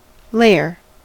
layer: Wikimedia Commons US English Pronunciations
En-us-layer.WAV